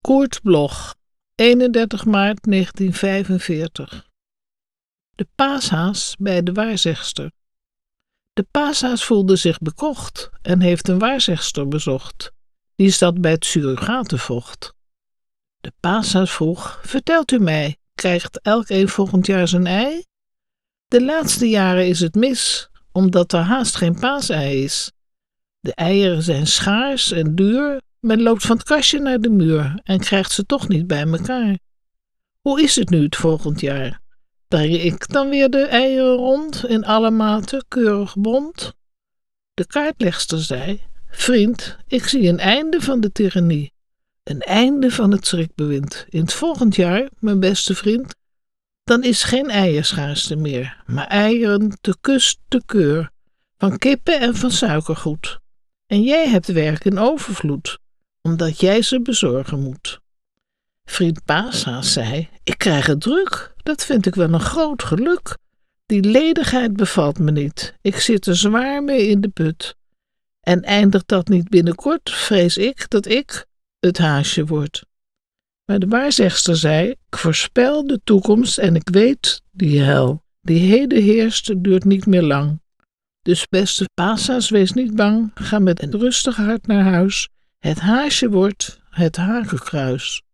Aufnahme: Karaktersound, Amsterdam · Bearbeitung: Kristen & Schmidt, Wiesbaden